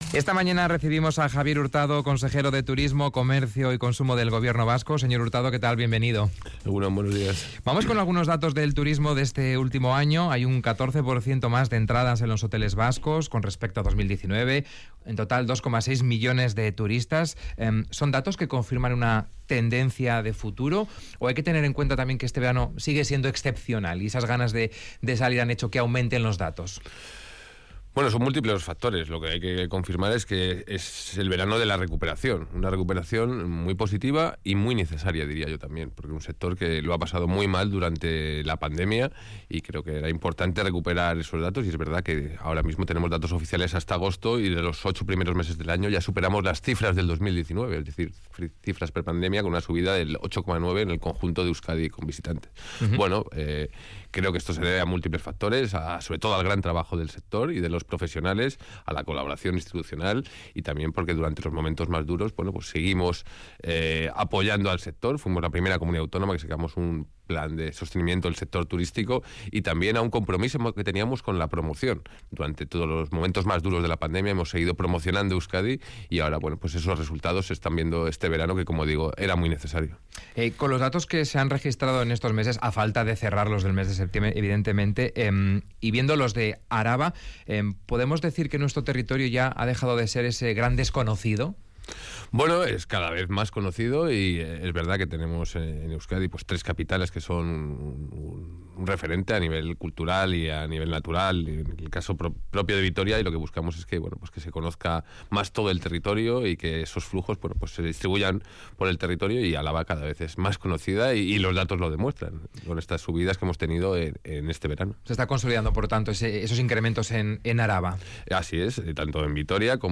Audio: Entrevistado en Radio Vitoria, el consejero vasco de Turismo, Javier Hurtado, ha confirmado un incremento del 8,9 por ciento en el número de visitantes